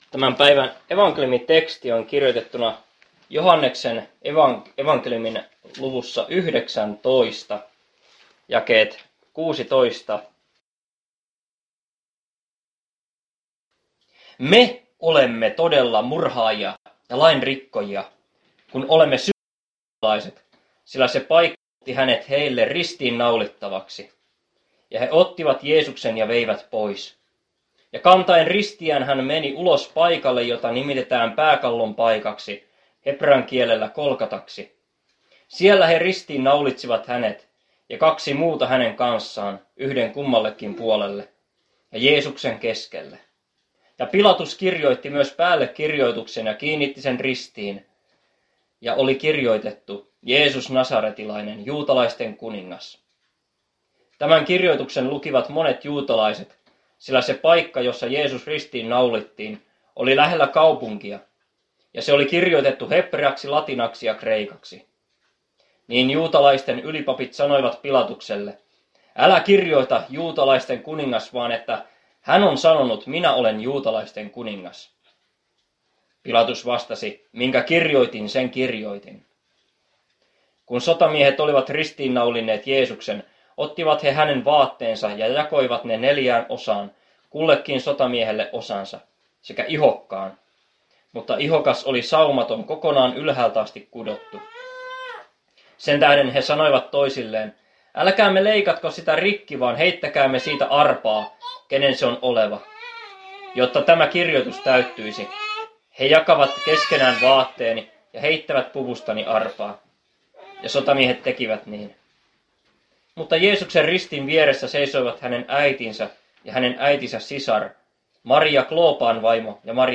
Ilmajoki